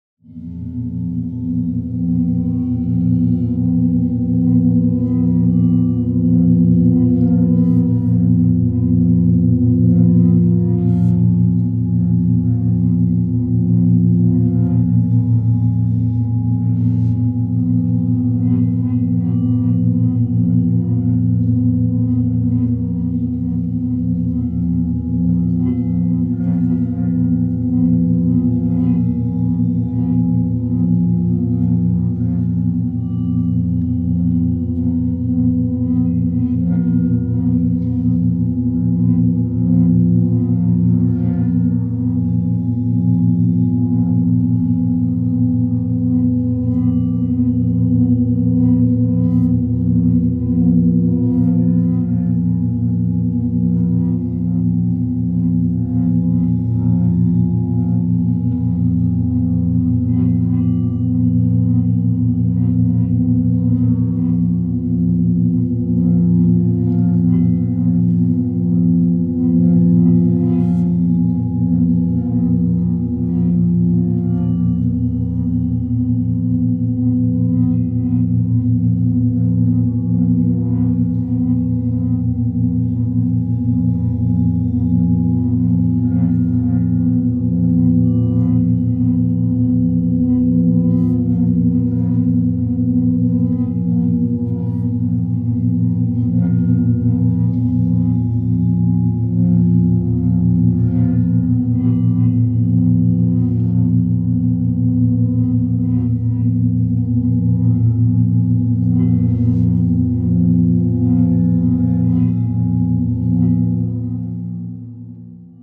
free horror ambience 2
ha-suffocate.wav